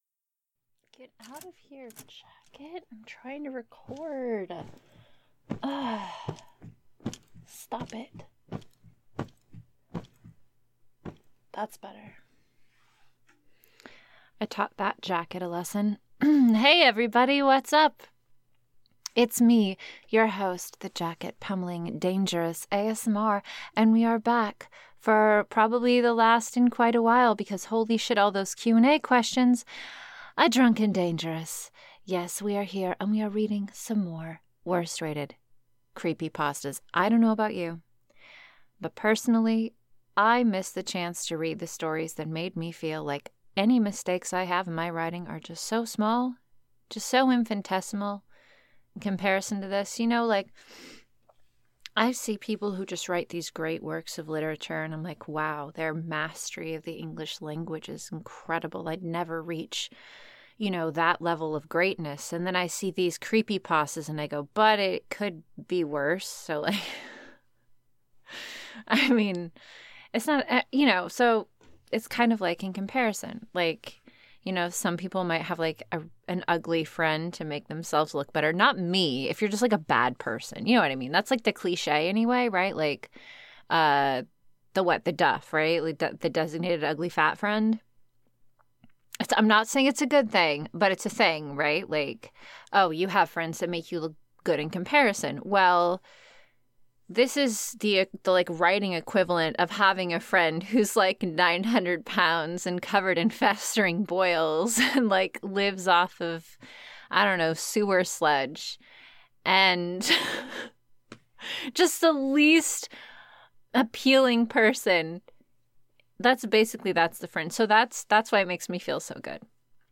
I hope you guys enjoy today's patron-exclusive upload, another Drunk & Dangerous Worst-Rated Creepypastas! Sit back and listen while I read and roast the worst-rated Creepypastas that live on the internet.